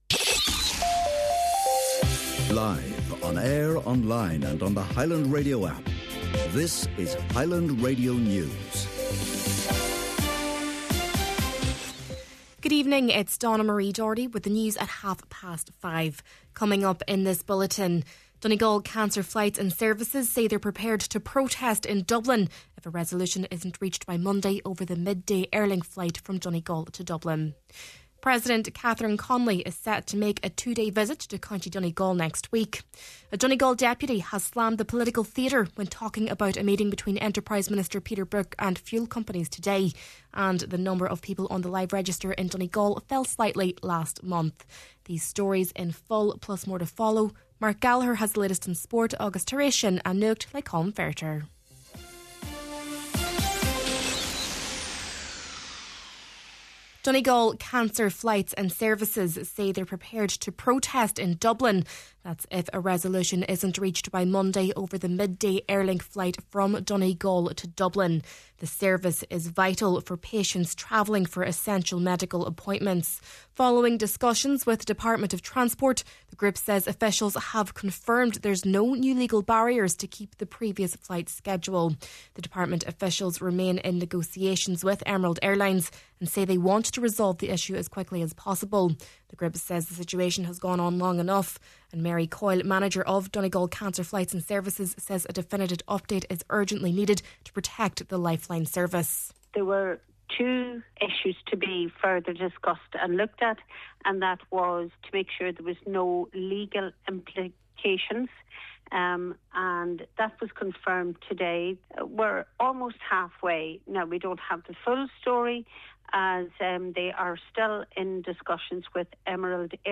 Main Evening News, Sport, An Nuacht and Obituary Notices – Friday, March 6th